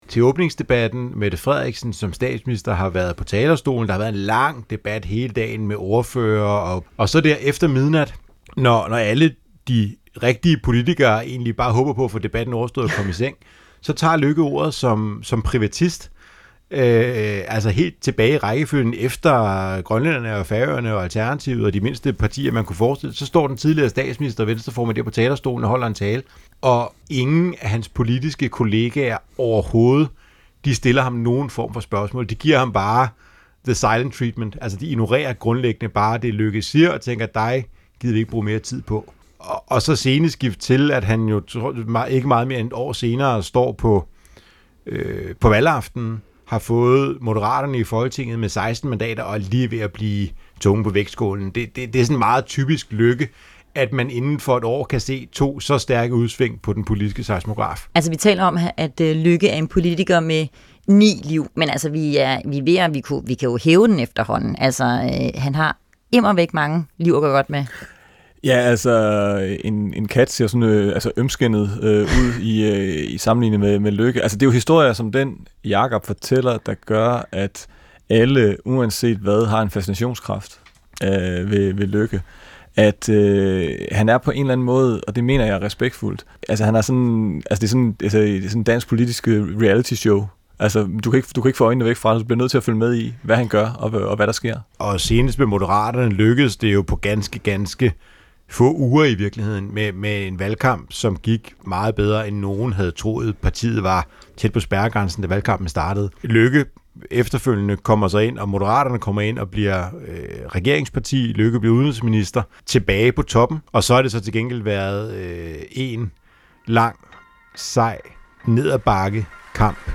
Få dansk politiks vigtigste historier. Kritiske interview med politikerne, landets bedste analytikere og DR's hold af politiske journalister i marken.